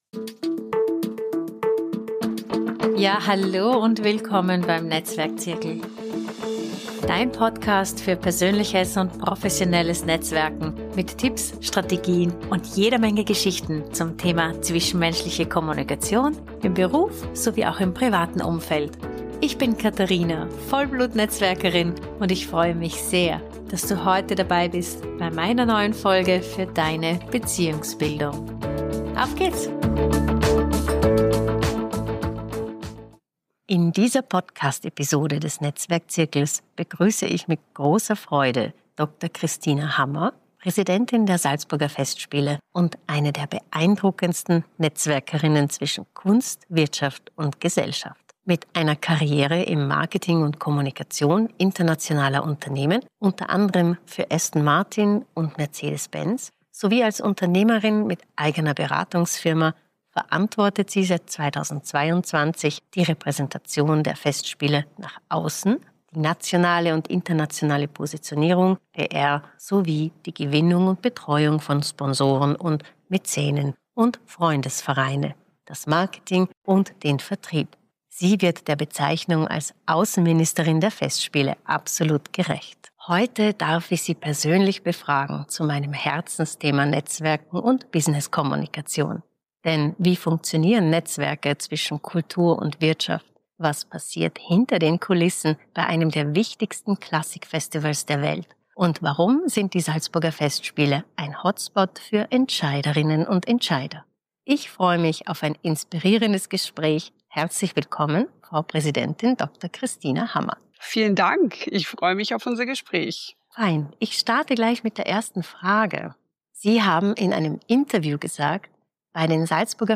#20: Salzburger Festspiele: Netzwerken zwischen Bühne & Business. Interview mit Präsidentin Kristina Hammer ~ NETZWERK-ZIRKEL Podcast